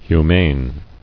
[hu·mane]